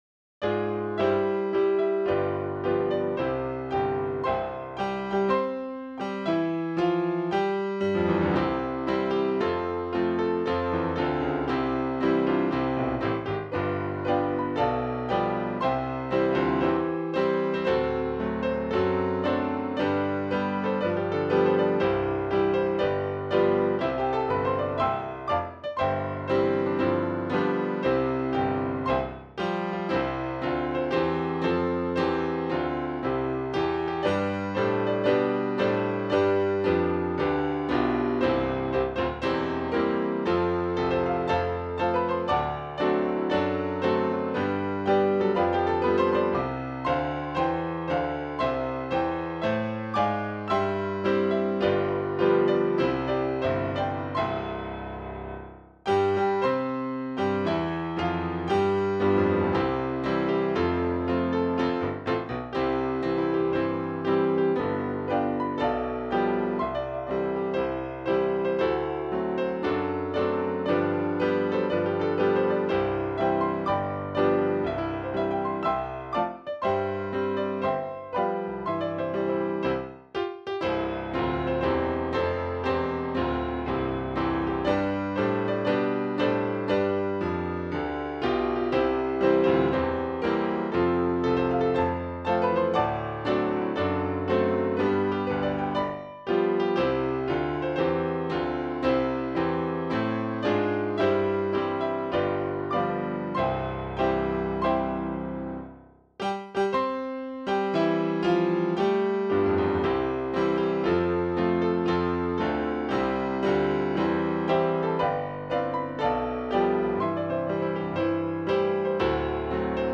Key: C
Traditional